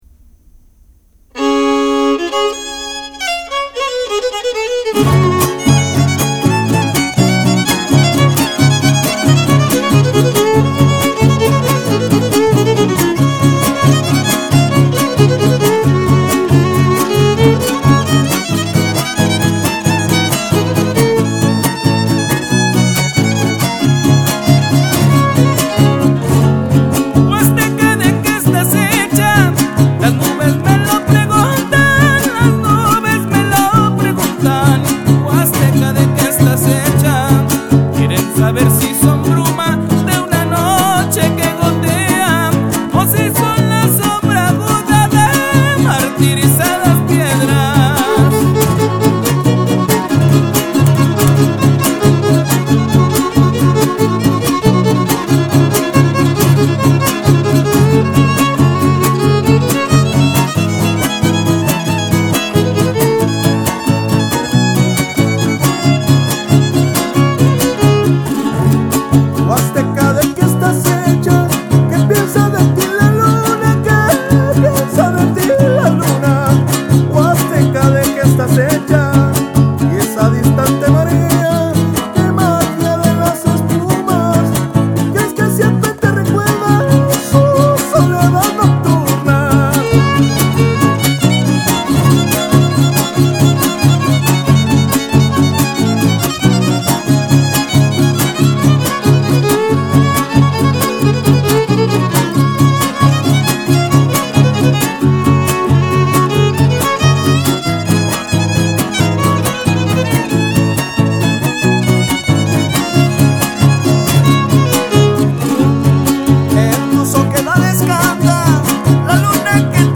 Huapango Music and Poetry
Huapango Music